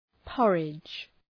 {‘pɒrıdʒ}